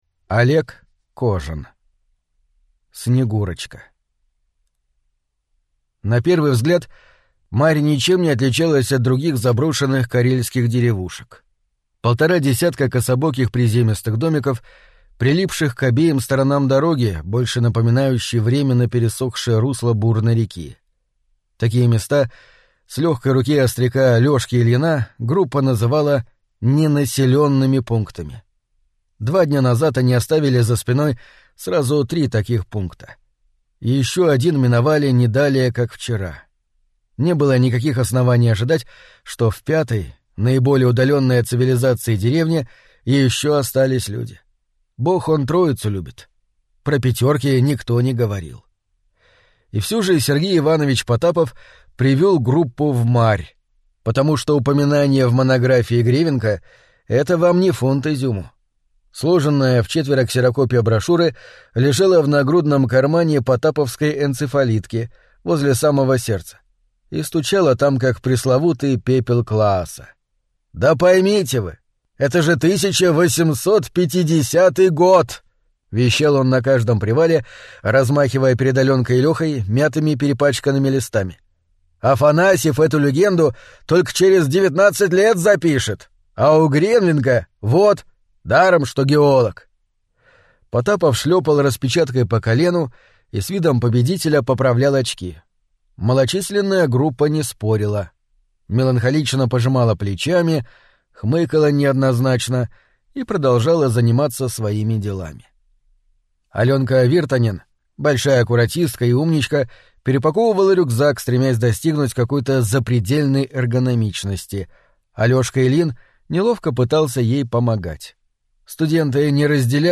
Аудиокнига Снегурочка и другие ужасы | Библиотека аудиокниг